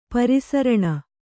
♪ pari saraṇa